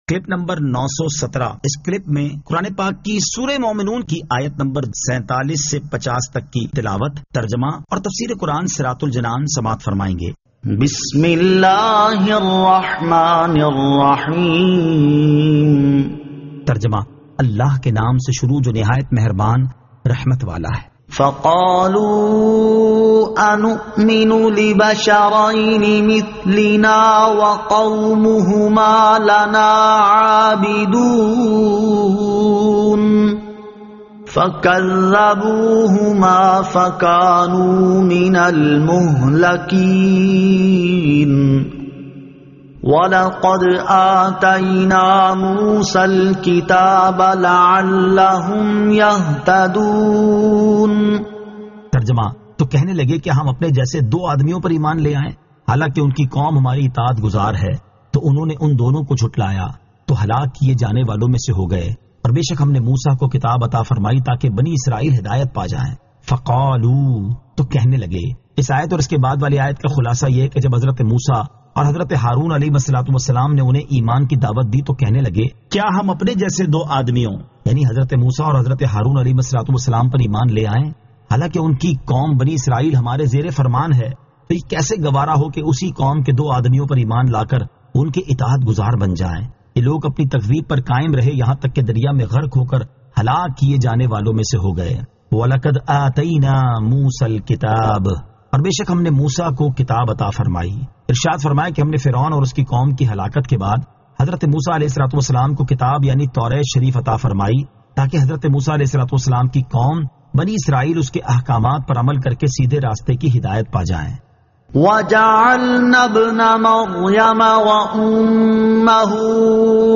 Surah Al-Mu'minun 47 To 50 Tilawat , Tarjama , Tafseer